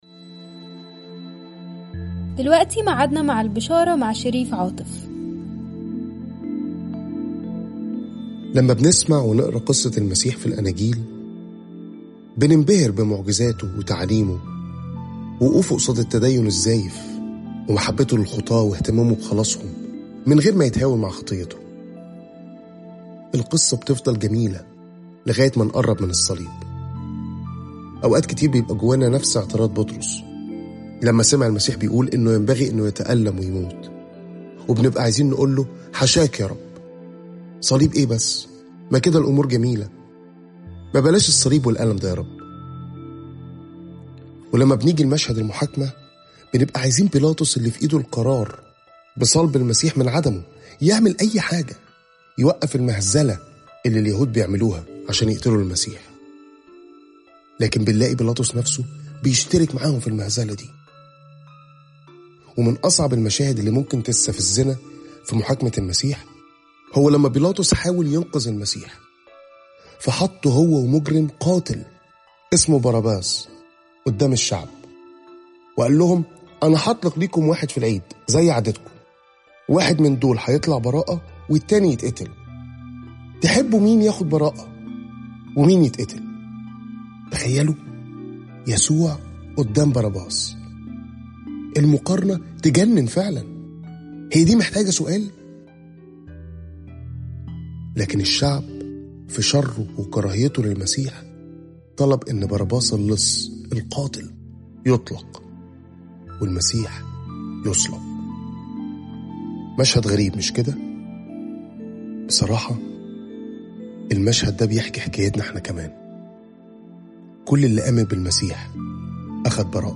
تأملات مُركّزة باللهجة المصرية عن عُمق وروعة صليب المسيح وقيامته